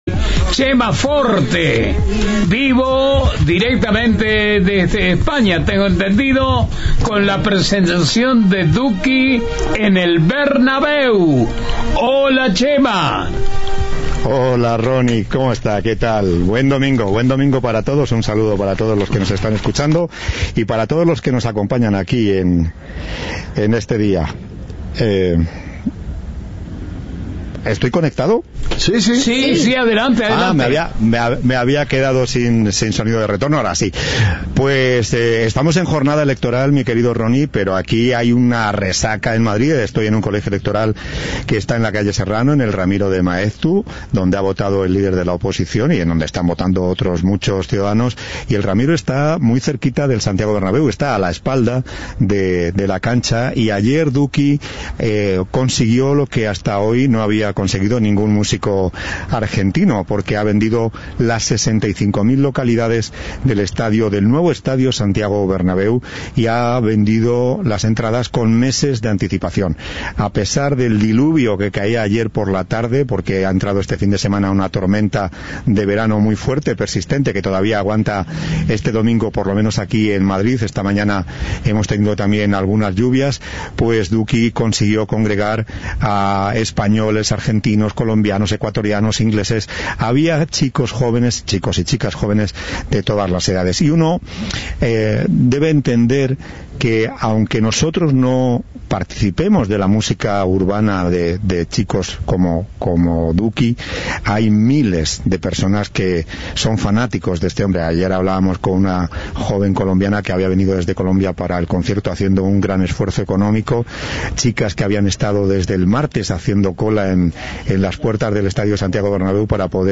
"Desde el martes a las 6 de la mañana estamos acá", dijo a Cadena 3 en la previa del show una joven española que esperaba en las afueras del Bernabéu.
Informe